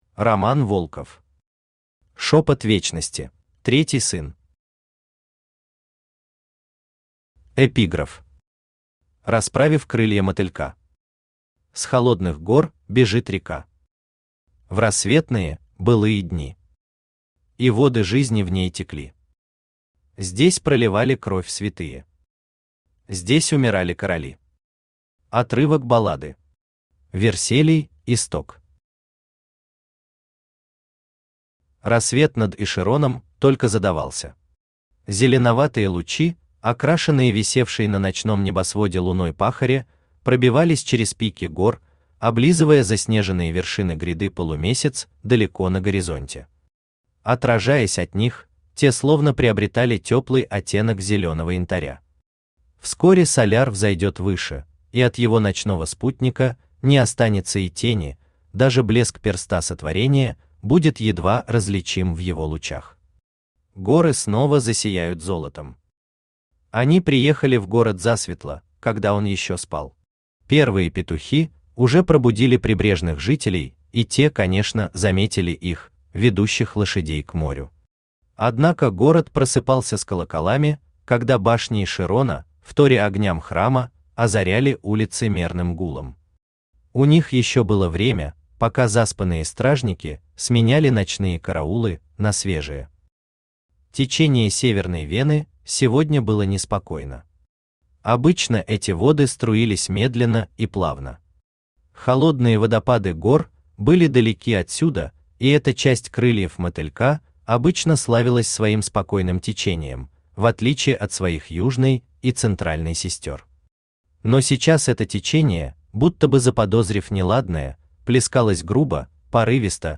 Аудиокнига Шепот Вечности | Библиотека аудиокниг
Aудиокнига Шепот Вечности Автор Роман Вячеславович Волков Читает аудиокнигу Авточтец ЛитРес.